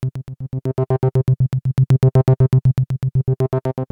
in one case, recording from the headphone output without clicks, and in the other, recording through the OB main out in daw with clicks.